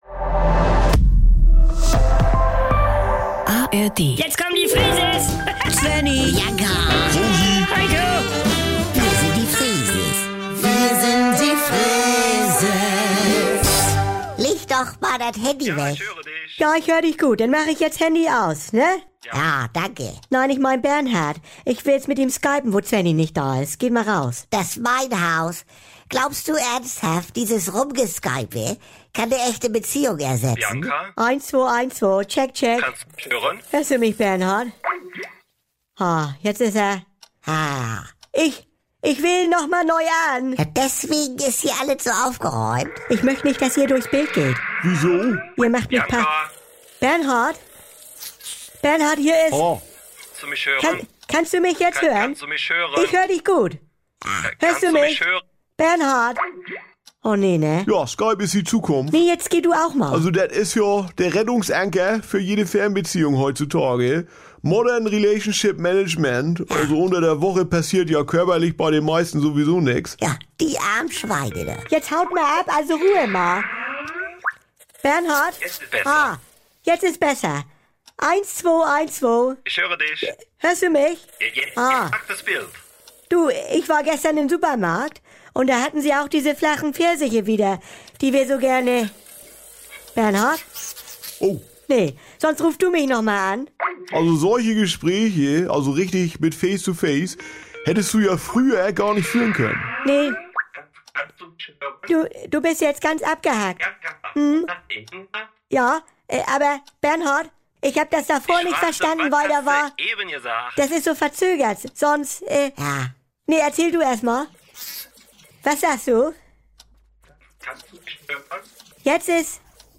oft ihr wollt: Die NDR 2 Kult-Comedy direkt aus dem